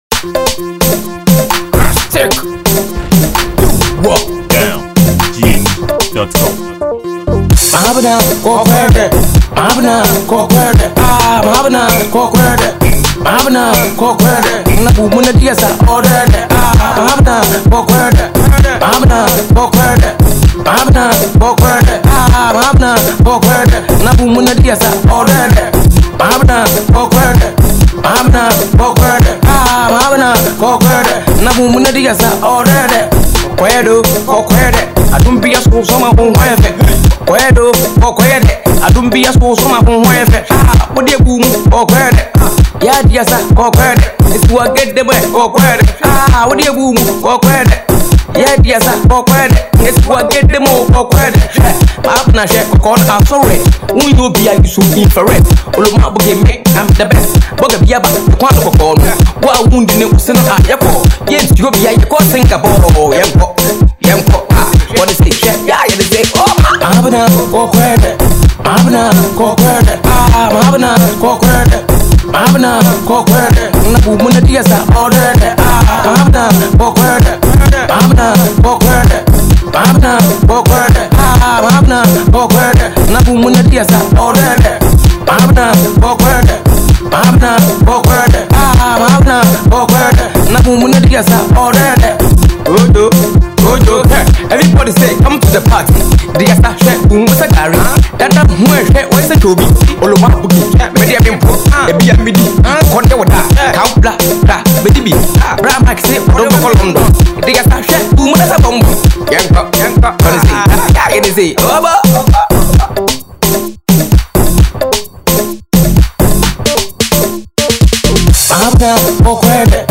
highlife rapper and a Ghanaian musician